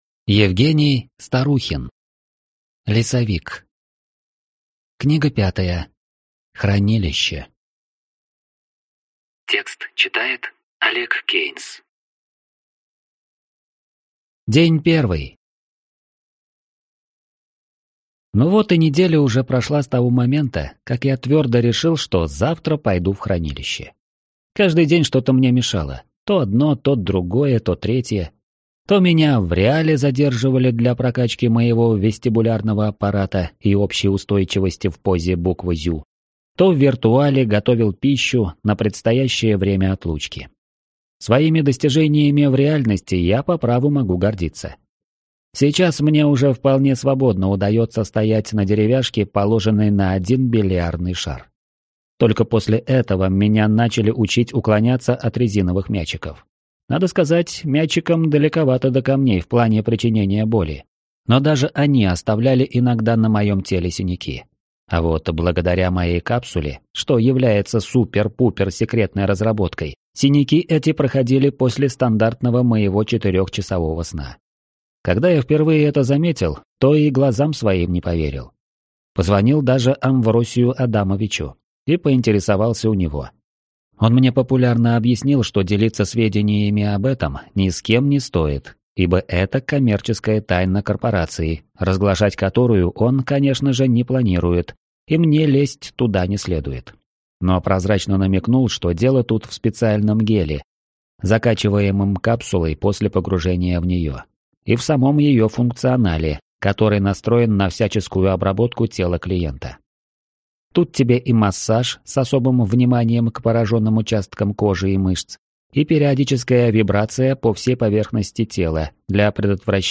Аудиокнига Лесовик. Хранилище | Библиотека аудиокниг
Прослушать и бесплатно скачать фрагмент аудиокниги